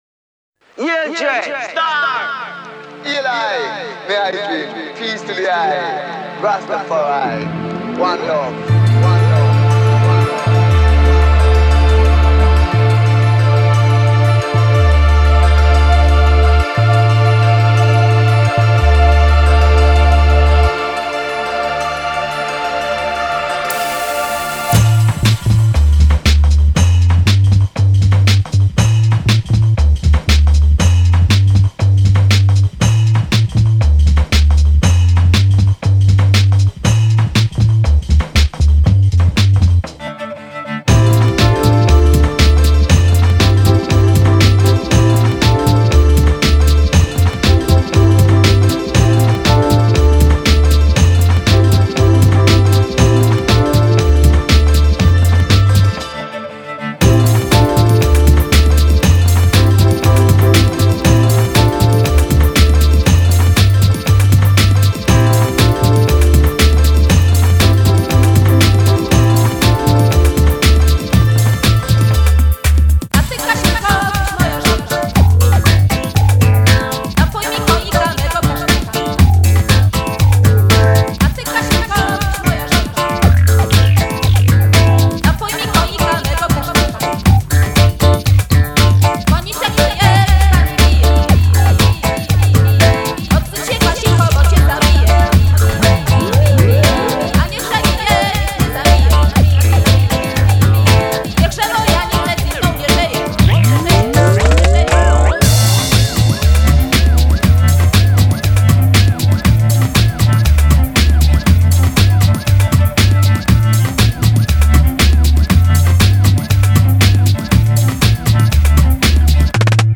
is a folk-ish band from Warsaw
Recently, the band released an album of remixed versions.